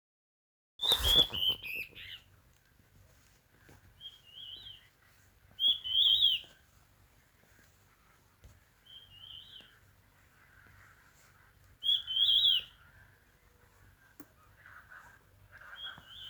Batará Estriado (Myrmorchilus strigilatus)
Nombre en inglés: Stripe-backed Antbird
Localidad o área protegida: Parque Nacional El Impenetrable
Condición: Silvestre
Certeza: Fotografiada, Vocalización Grabada
Batara-estriado-1_1_1_1.mp3